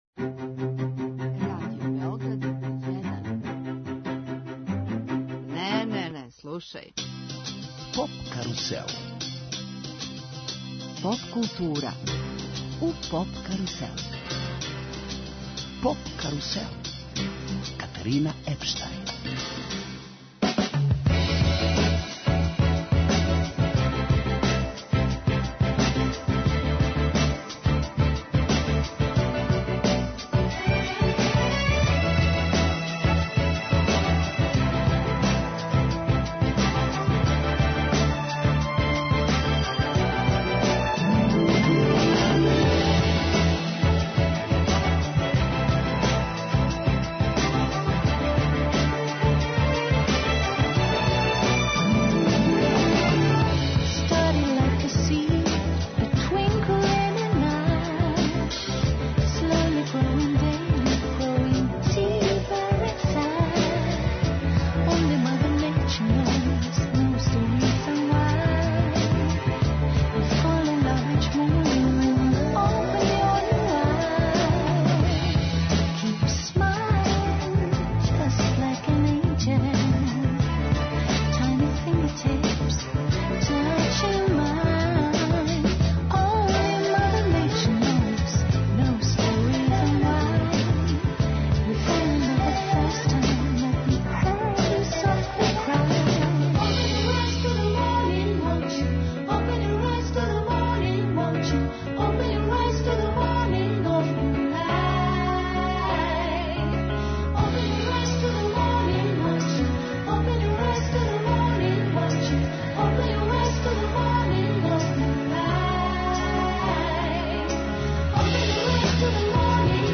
Емисија из домена популарне културе.